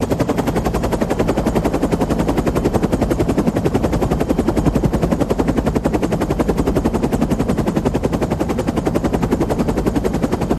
Helicopter Blades Whop Loop